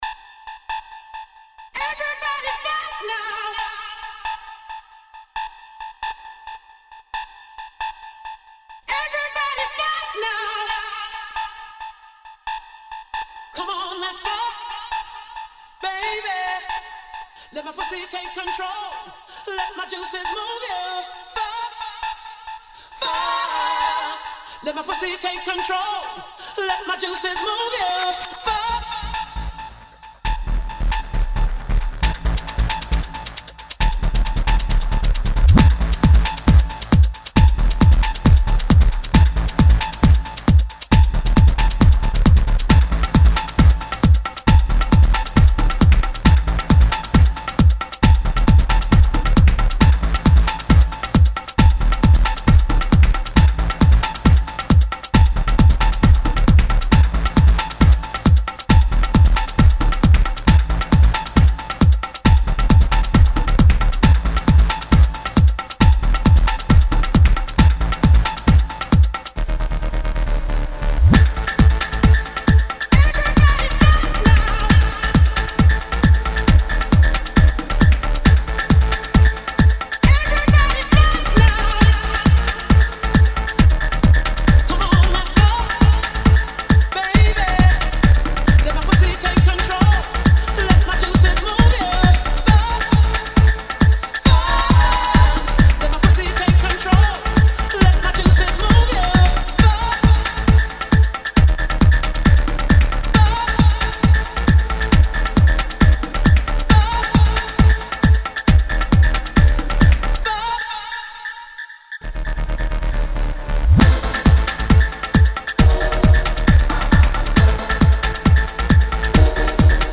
dance/electronic
Funky naughty tune
House
Electro